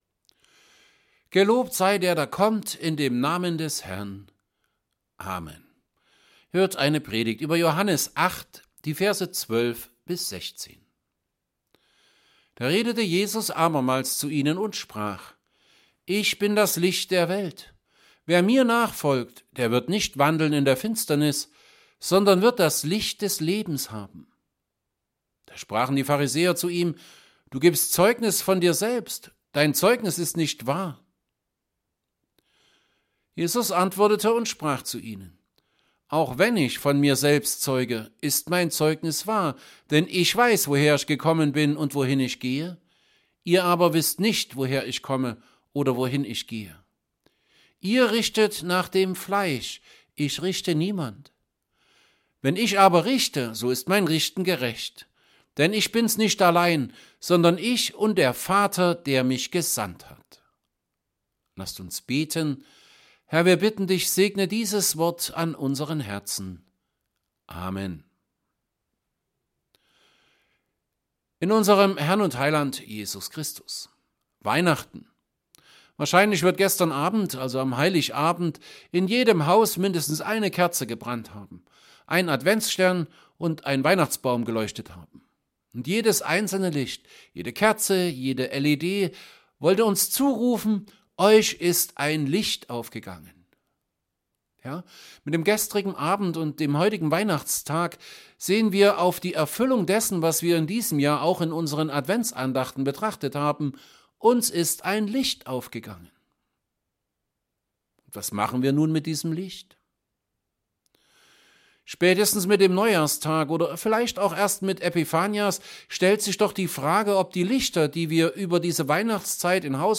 Weihnachtsfeiertag , Predigt , St. Paulusgemeinde Saalfeld « Christvesper 1.
Predigt_zu_Johannes_8_12b16.mp3